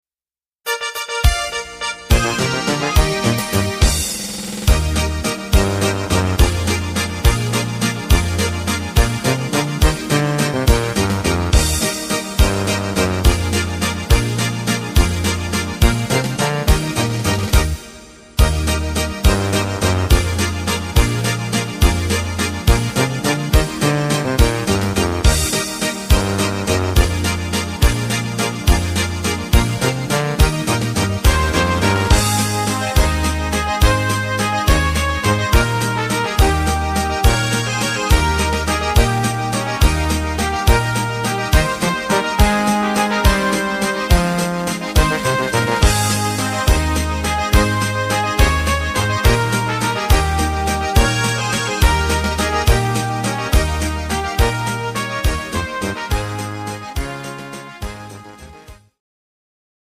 Klarinette